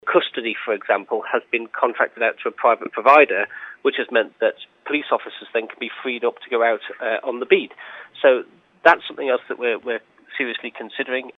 Minister Juan Watterson says first on the list would be changing the way the custody block is staffed: